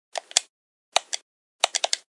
Remote Control Machine